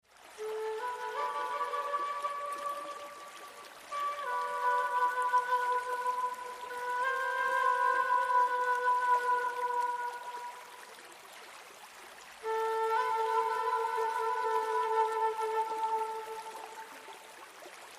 Relaxing Meditation, Stress Relief, Meditation sound effects free download
Relaxing Meditation, Stress Relief, Meditation Music , Deep Sleeping